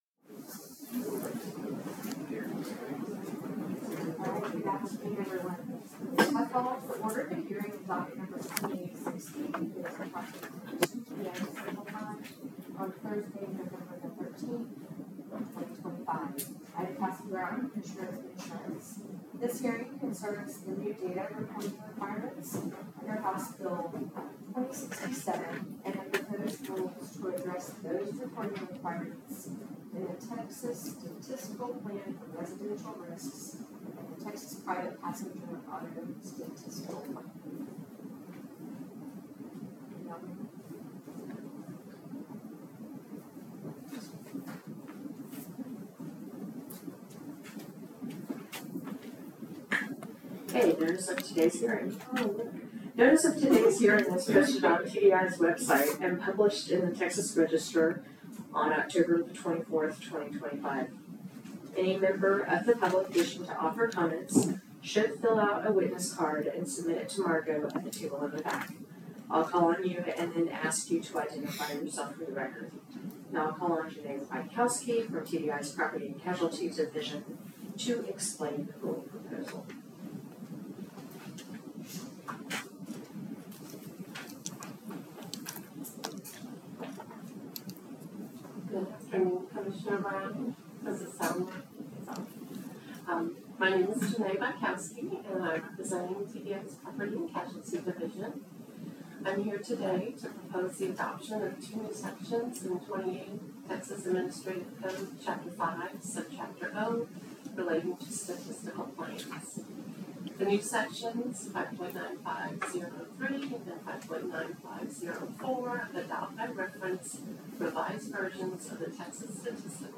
Docket No. 2860 – Residential and PPA Statistical Plans Rule Hearing